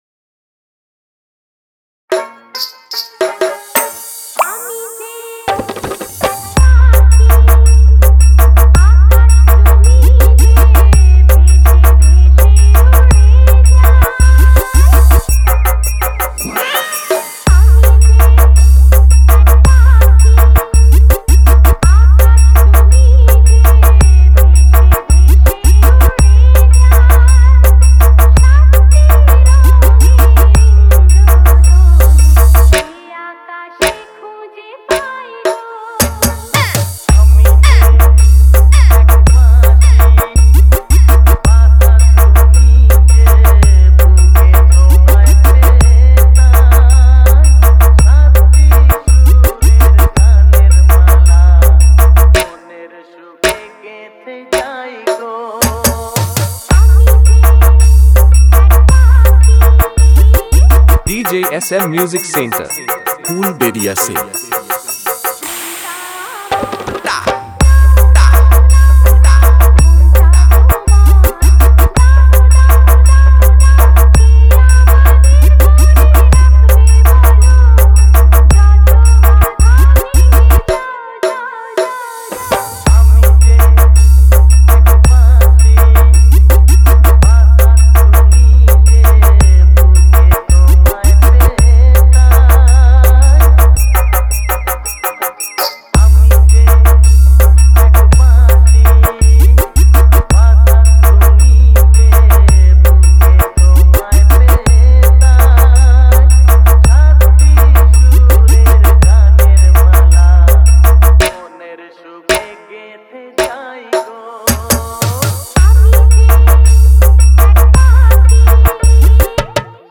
বাংলা হামবিং মিক্স